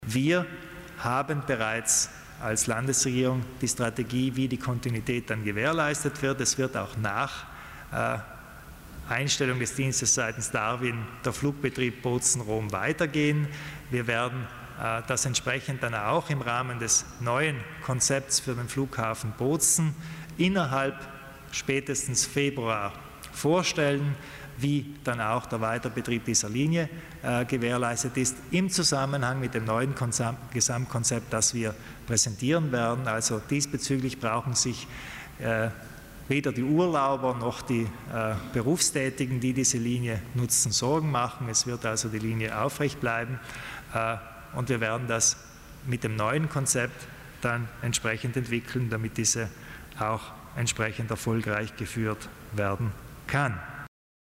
Landeshauptmann Kompatscher erläutert die Neuheiten in Sachen Flugbetrieb